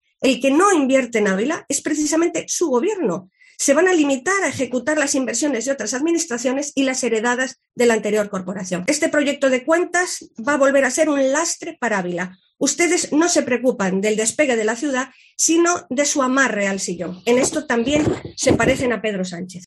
Sonsoles Sánchez-Reyes, portavoz PP. Pleno presupuestos 2022